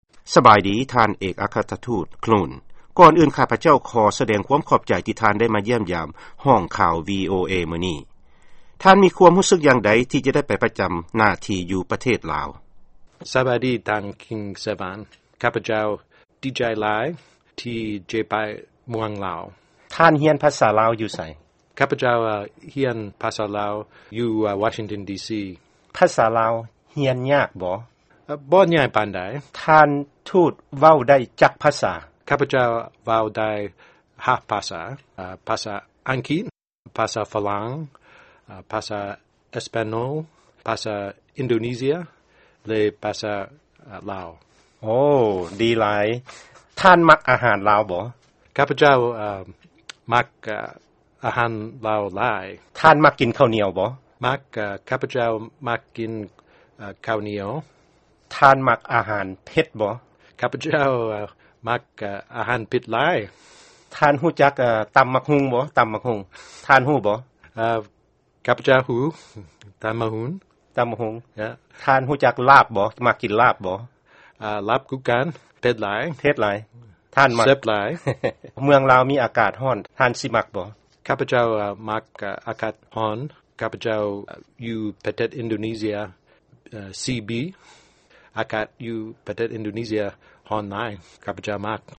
ຟັງເອກອັກຄະລັດຖະທູດ Daniel Clune ເວົ້າພາສາລາວ